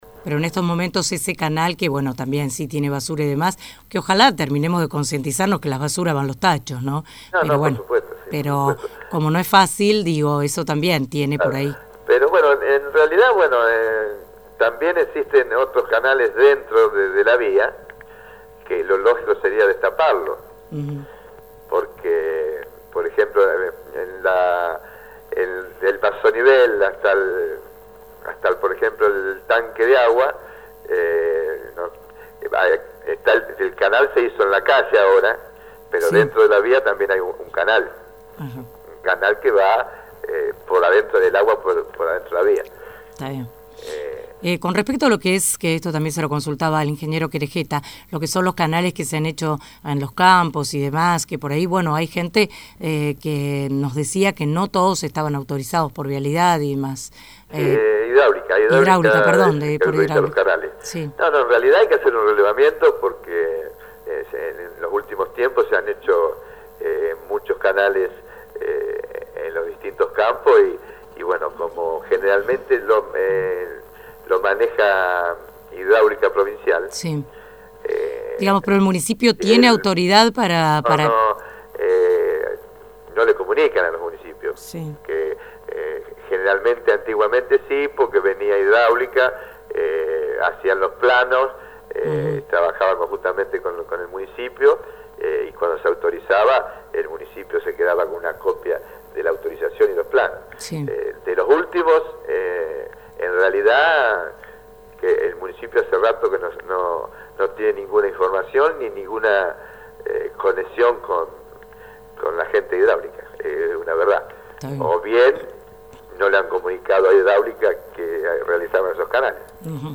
21/4/14- En la mañana de hoy, el Delegado Municipal Néstor Daniel Micucci se refirió Sobre Nivel a los últimos trabajos que se vienen realizando en la limpieza y profundización de los canales pluviales que van desde Rawson y hacia la Laguna Las Toscas y Laguna de Ranchos, luego de que varios sectores de la localidad debieran soportar gravísimos anegamientos –desde el domingo 6 de abril- por varios días, y habiendo aún lugares con serios problemas.